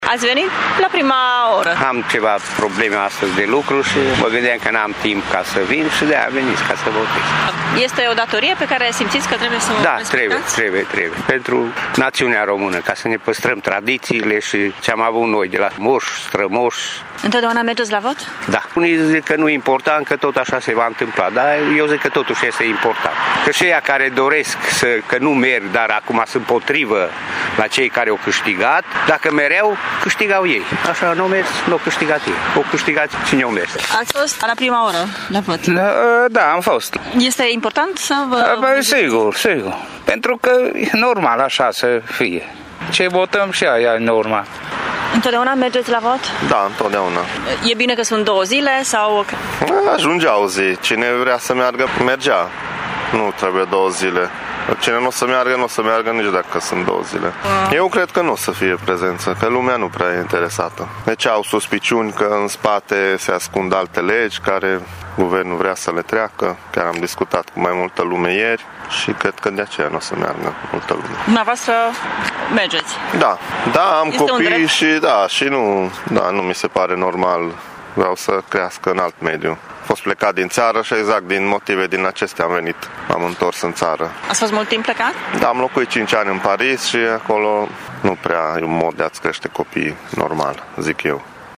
Doar câțiva târgumureșeni s-au încumetat să iasă din casă în această dimineață, în prima oră după deschiderea secțiilor de vot. Oamenii spun că de fiecare dată votează la alegeri deoarece fiecare vot contează: